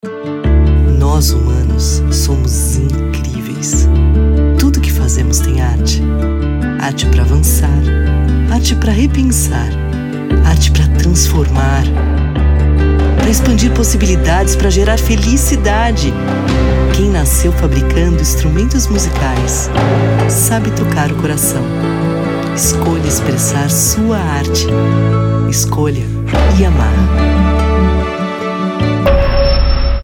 Feminino
YAMAHA (institucional, inspiracional)
Voz Madura 00:30